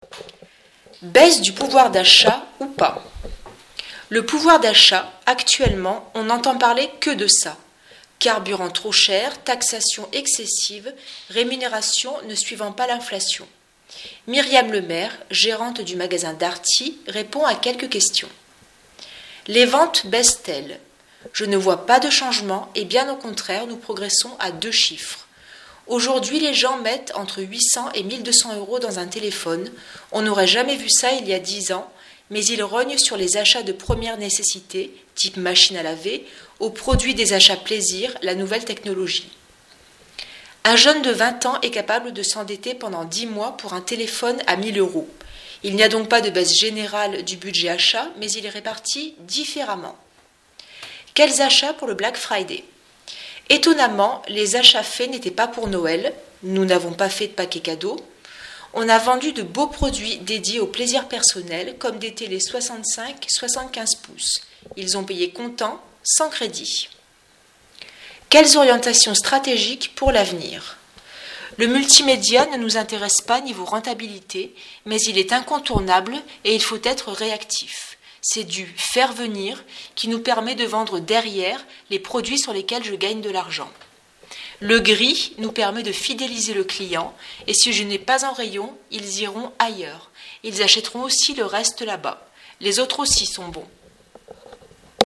répond à quelques questions sur les tendances de consommation.